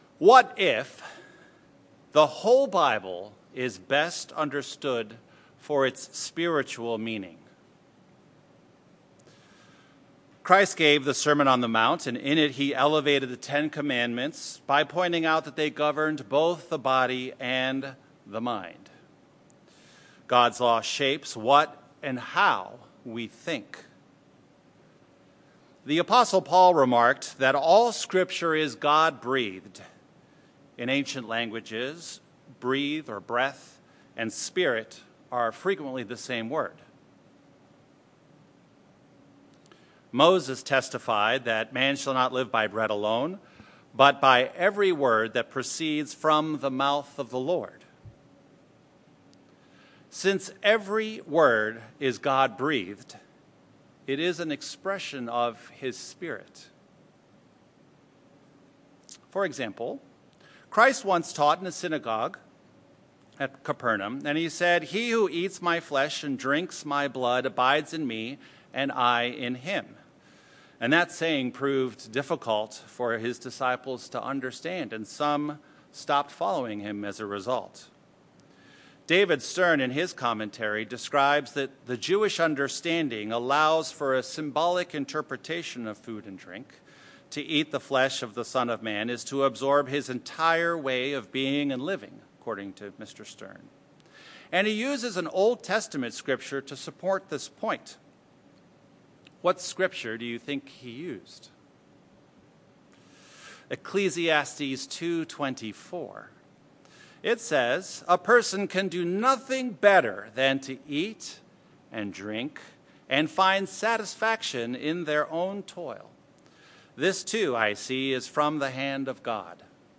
This sermon reviews four (4) places where the Bible interprets the Bible to reveal the Spiritual implications behind God’s instructions. How might understanding all Scripture in a spiritual context shape our thinking, modify our decision making process, and accelerate becoming like Christ and our Heavenly Father?